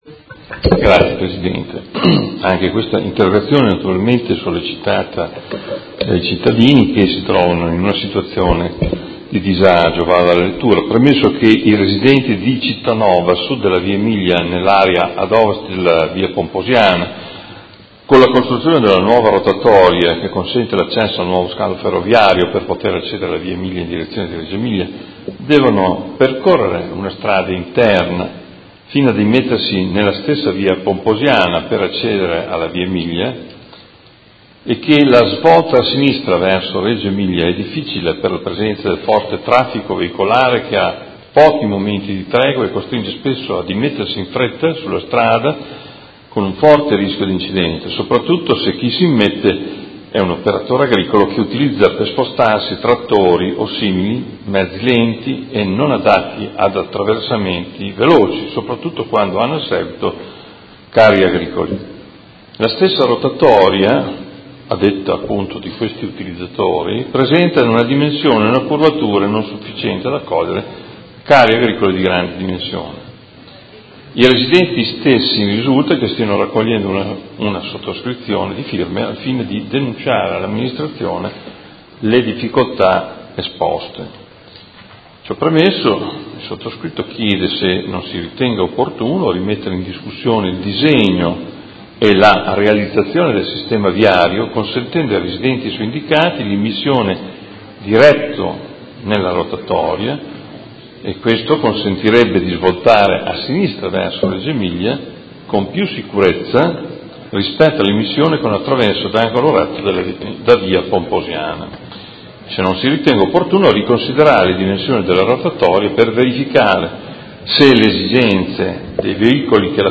Seduta del 13/07/2017 Interrogazione del Consigliere Morandi (FI) avente per oggetto: La nuova viabilità della Via Emilia a Cittanova con la creazione della rotatoria necessaria all’accesso per il nuovo scalo ferroviario, crea notevoli disagi ai residenti di Cittanova a sud della Via Emilia